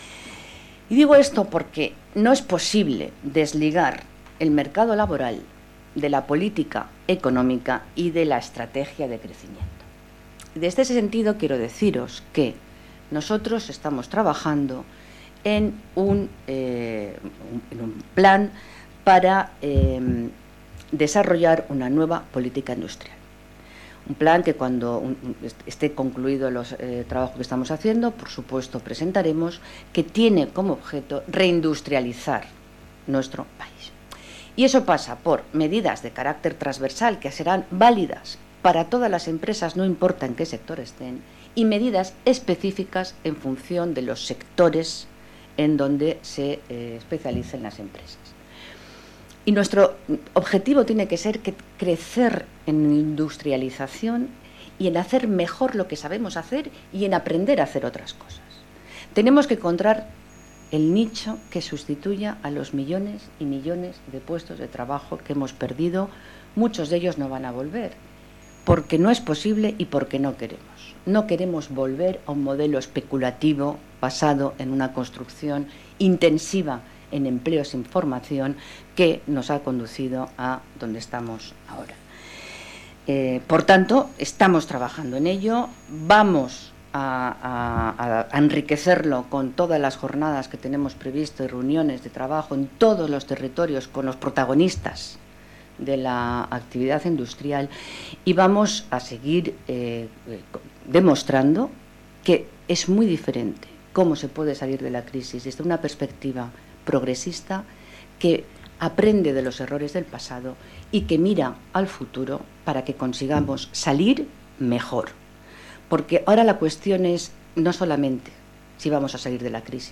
Jornada "Devaluación salarial" organizada en el Congreso de los Diputados junto a Economistas frente a la crisis. 9/10/2013